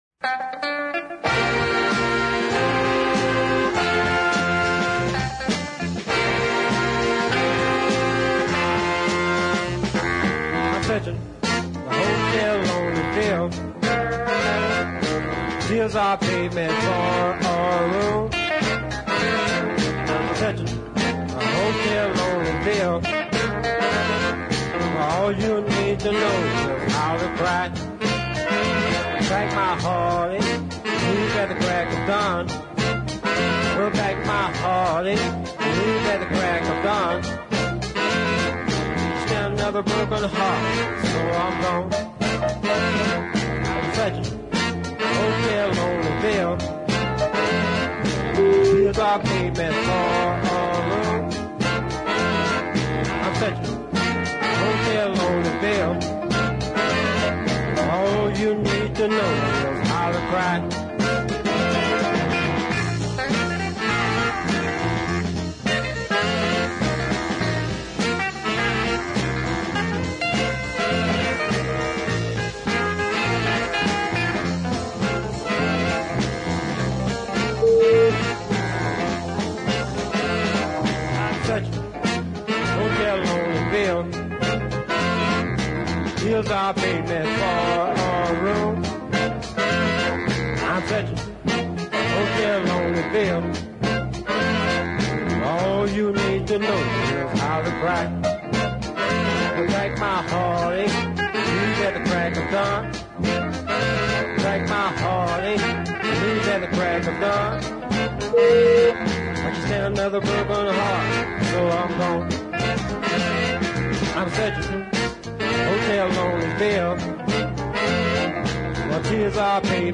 slower funky
features a fine horn section
can’t get enough of that baritone sax!